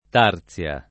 [ t # r ZL a ]